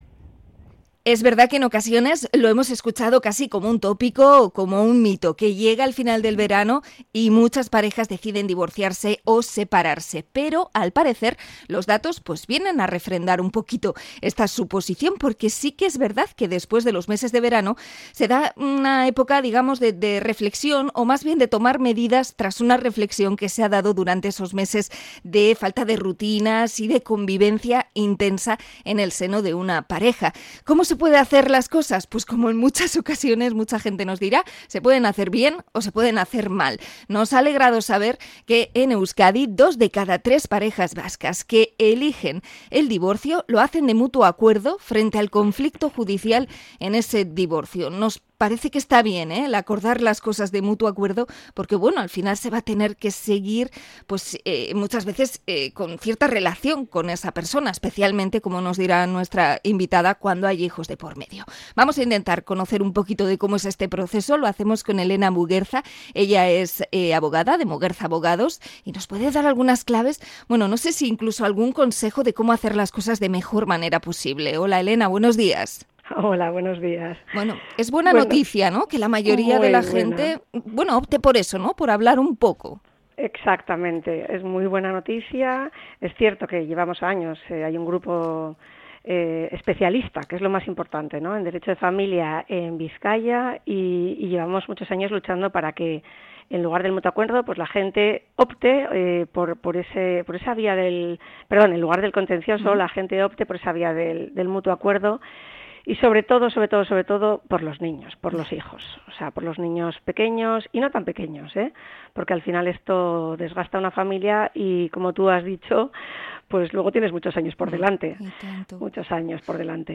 Entrevista a abogada sobre separaciones de mutua acuerdo
INT.-ABOGADA-DIVORCIOS.mp3